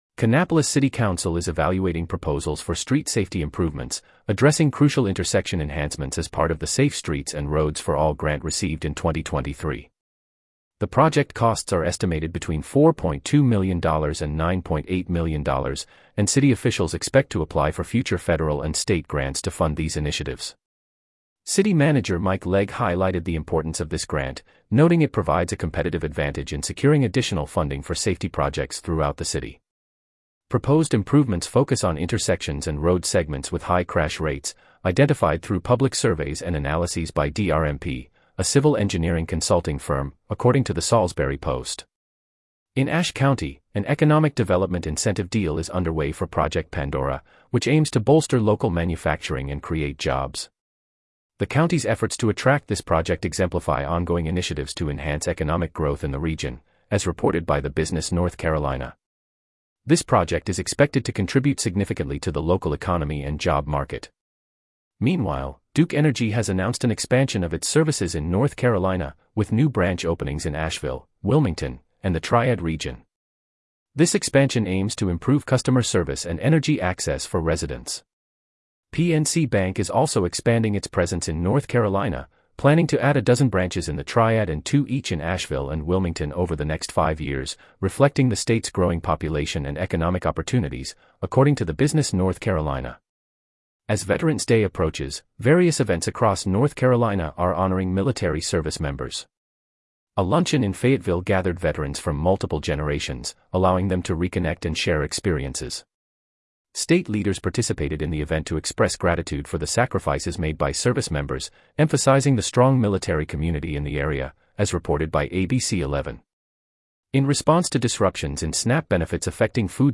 North Carolina News Summary